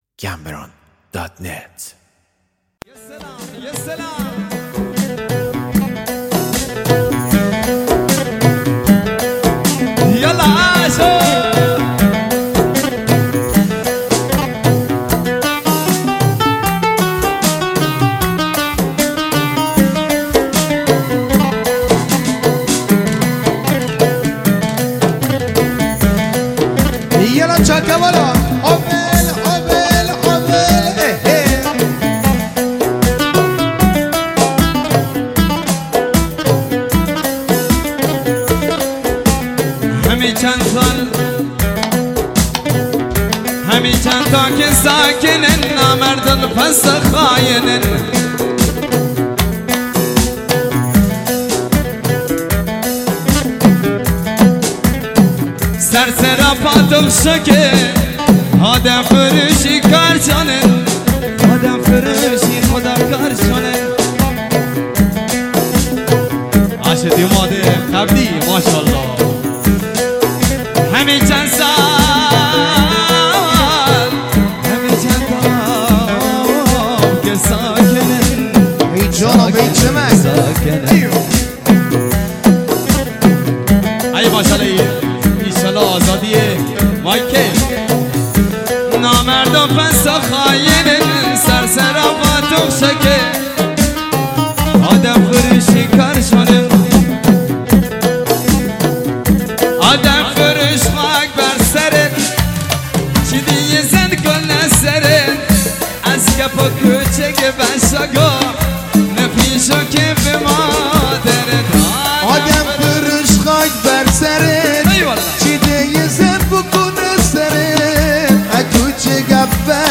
آهنگ بستکی